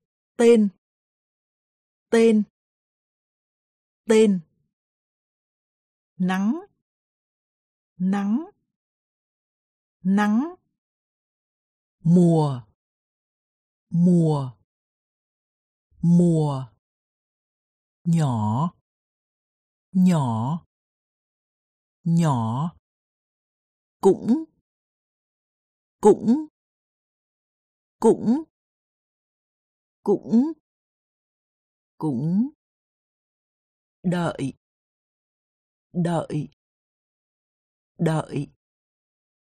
Аудиокнига Разговорный вьетнамский язык. Курс для начинающих. Аудиоприложение | Библиотека аудиокниг